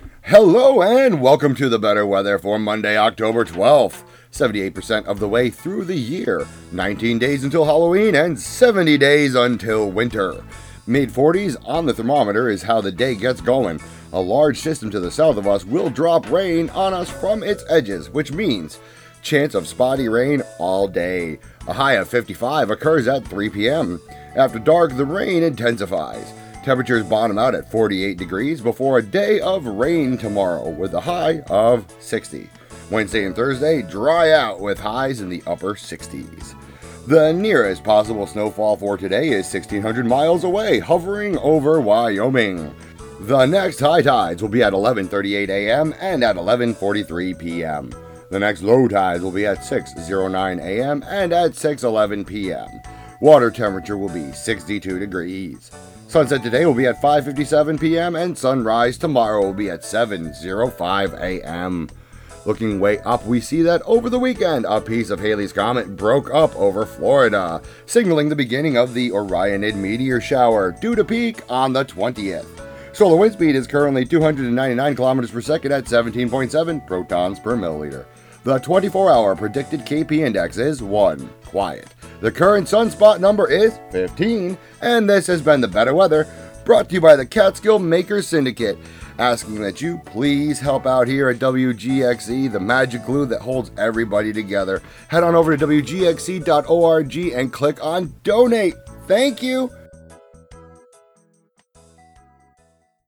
Today's local weather.